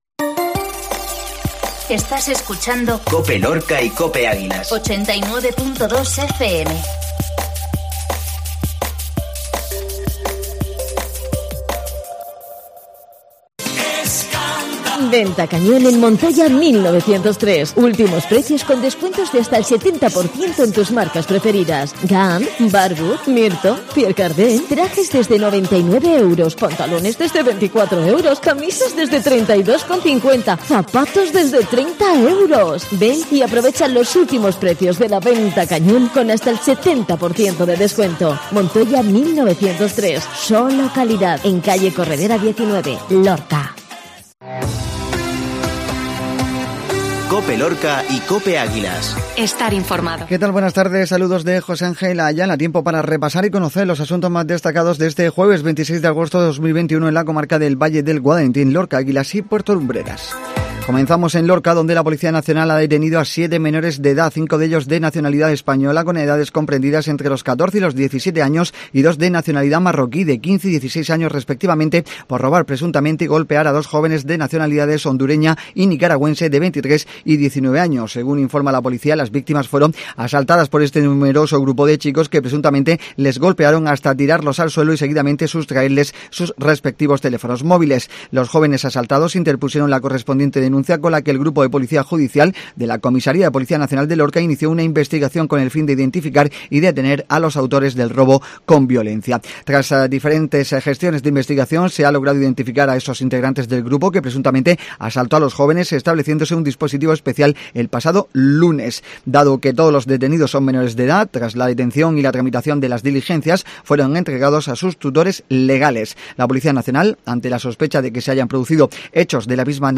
INFORMATIVO MEDIODÍA COPE